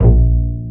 PizzBass.wav